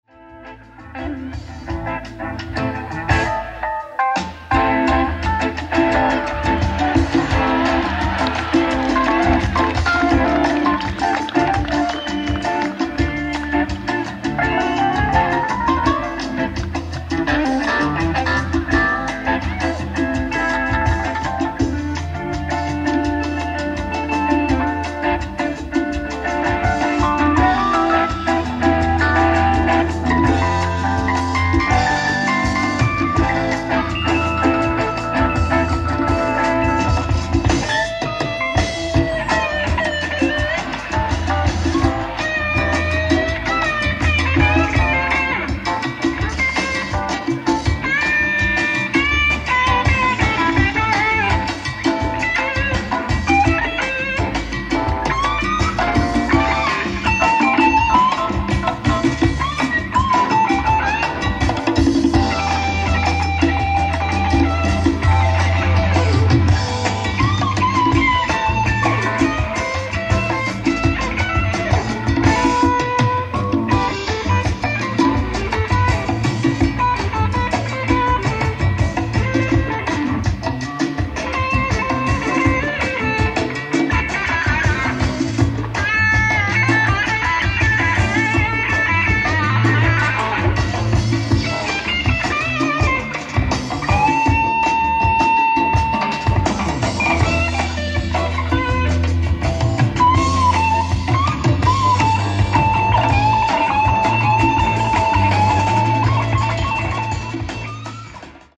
ライブ・アット・バークリー・ジャズフェスティバル、グリークシアター、バークリー 05/25/1980
発掘された極上オーディエンス音源！！
※試聴用に実際より音質を落としています。